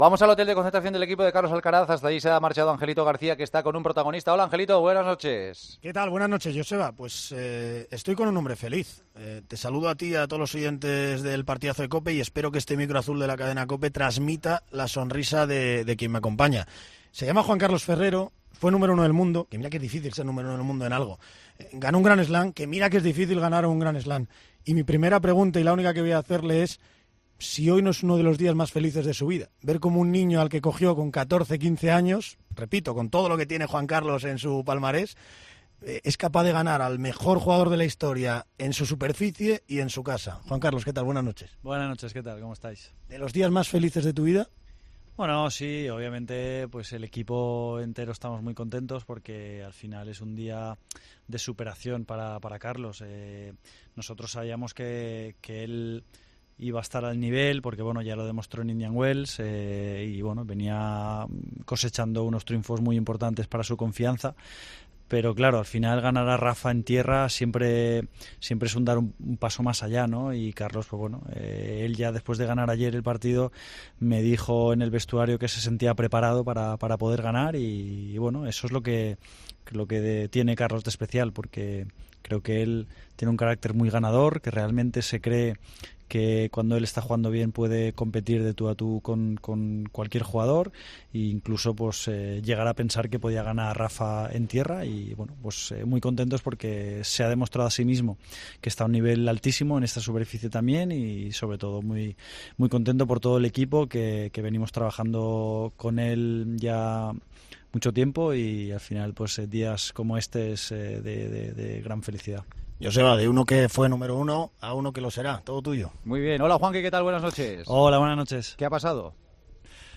Este viernes, el extenista y entrenador de Carlos Alcaraz, Juan Carlos Ferrero, estuvo en El Partidazo de COPE para analizar la histórica victoria de Alcaraz sobre Rafa Nadal en los cuartos de final del Mutua Madrid Open.